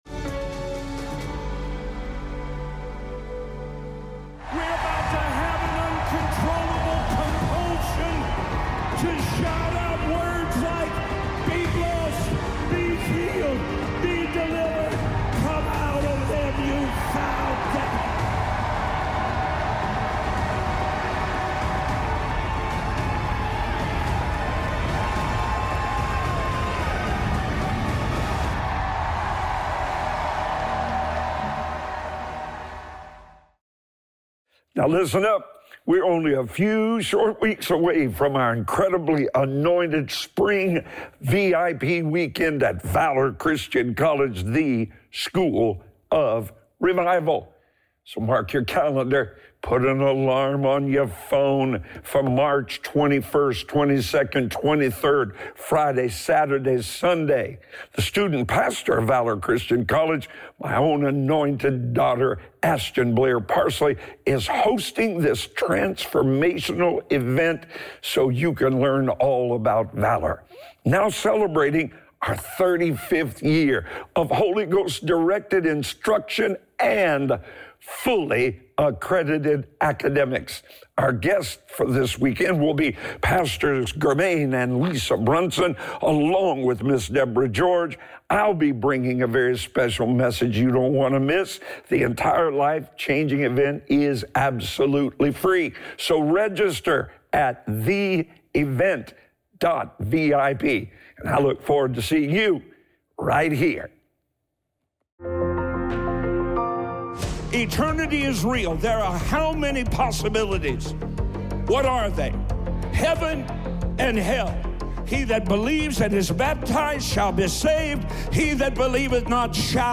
Audio only from the daily television program Breakthrough